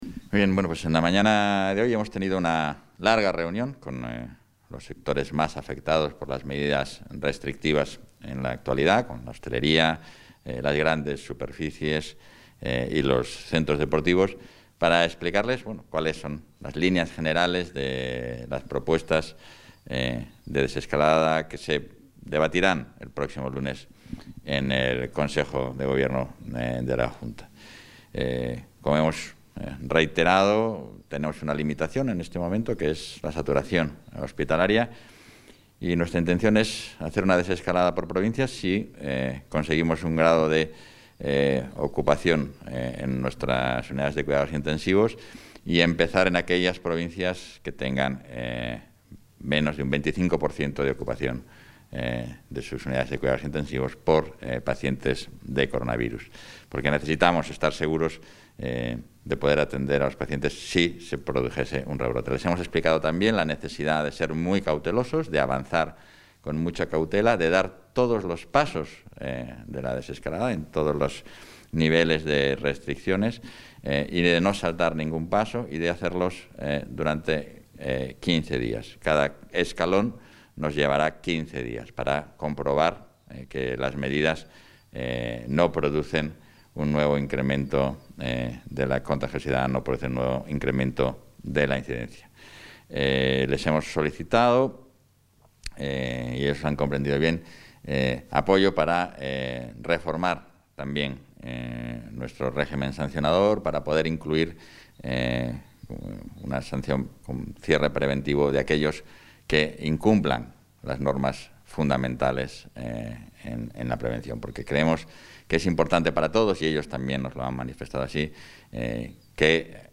Declaraciones del vicepresidente y portavoz.